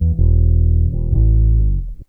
BASS 27.wav